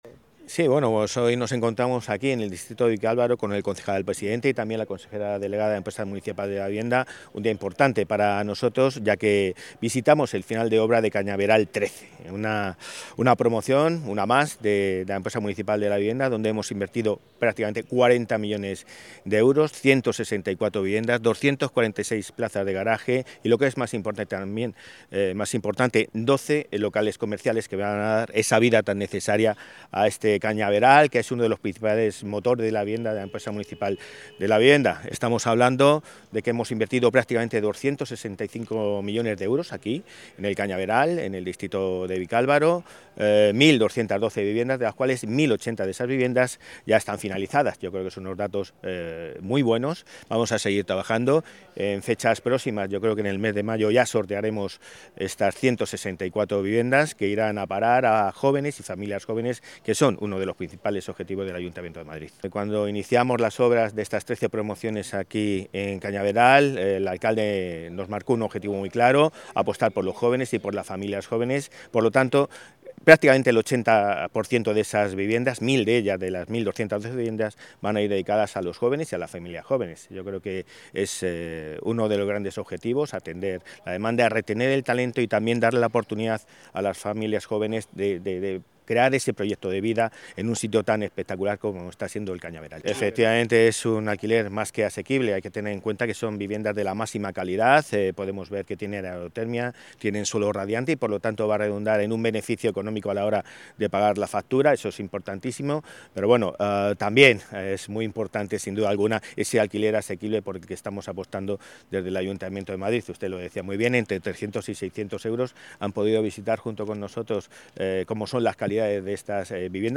Nueva ventana:El delegado de Políticas de Vivienda y presidente de EMVS Madrid, Álvaro González: